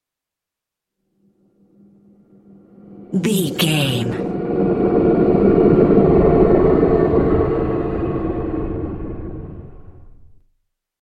Vehicle sci fi pass by car ship
Sound Effects
strange
high tech